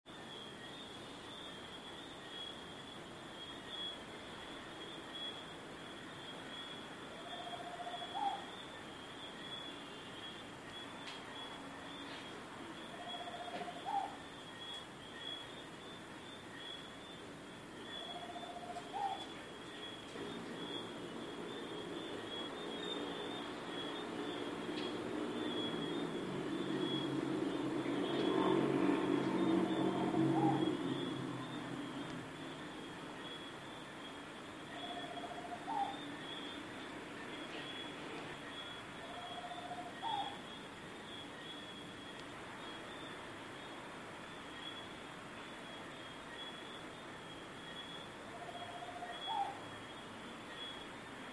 Tropical Screech Owl (Megascops choliba)
Life Stage: Adult
Detailed location: Zona Urbana
Condition: Wild
Certainty: Recorded vocal